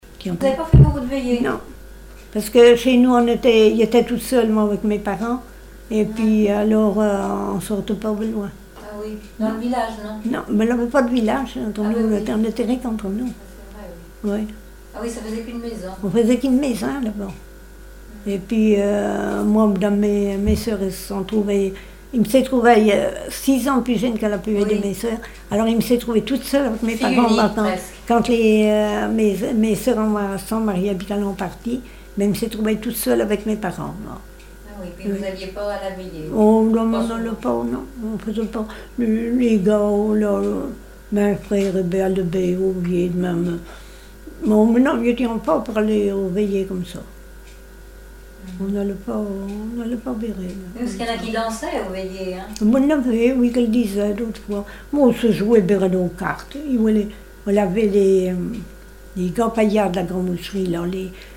Témoignages et chansons
Catégorie Témoignage